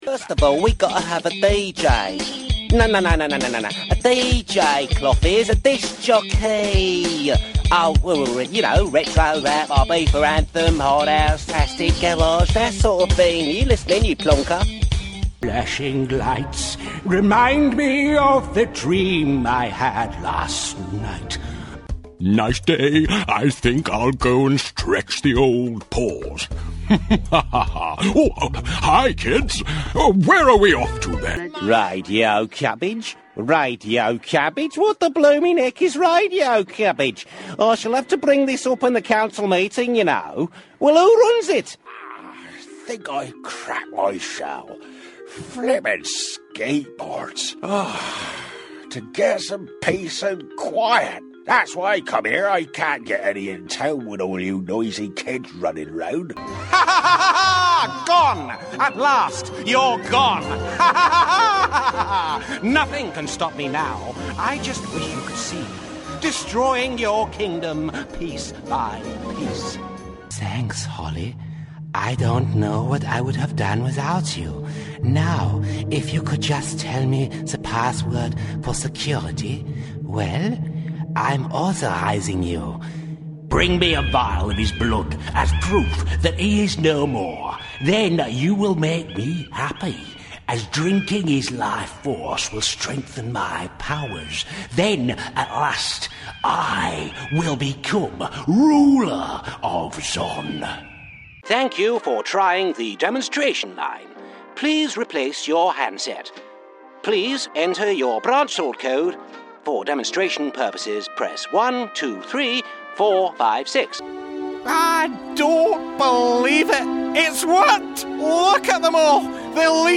Male
English (British)
Adult (30-50), Older Sound (50+)
An award winning intelligent warmth and soft English style that will arrest your ears with vocal charisma and educated credibility.